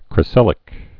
(krĭ-sĭlĭk)